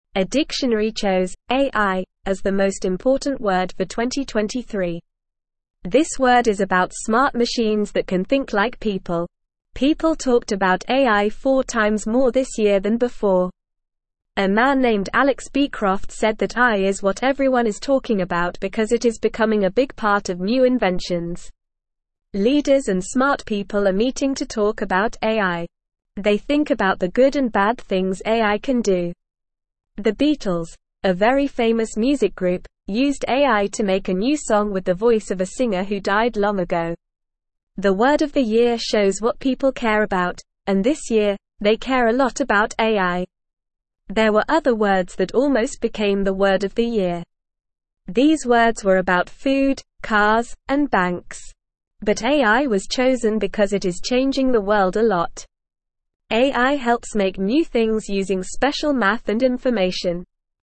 Normal
English-Newsroom-Lower-Intermediate-NORMAL-Reading-AI-The-Word-of-the-Year.mp3